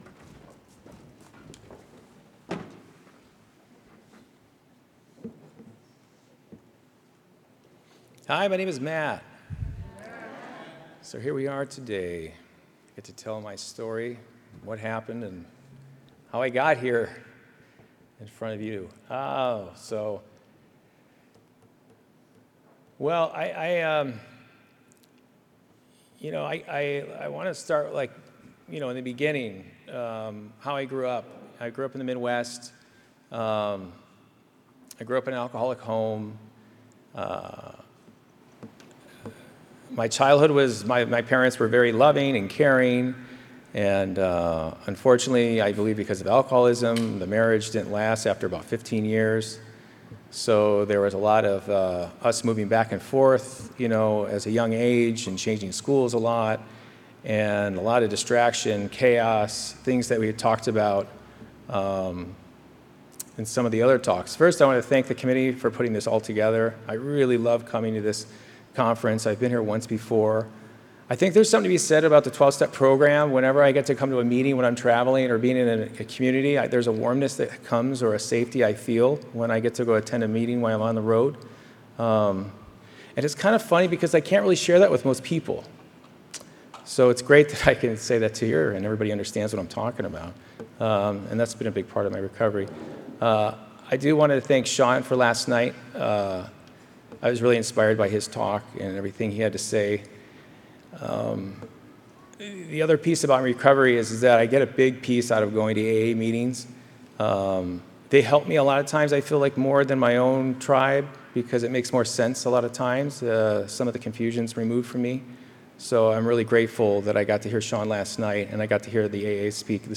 Encore Audio Archives - 12 Step Recovery 35th Indian Wells Valley Roundup